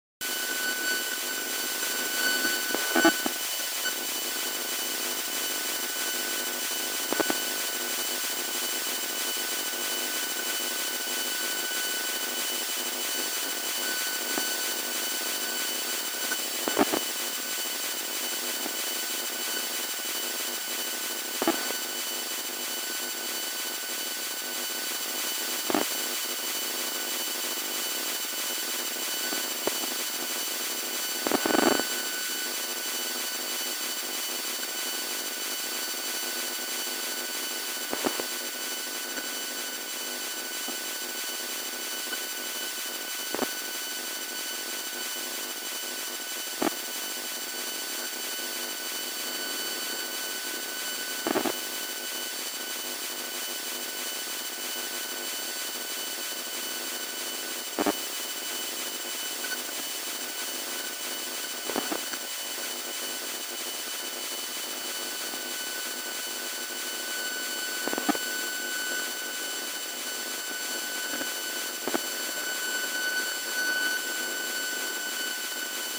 3G_WCDMA_1.mp3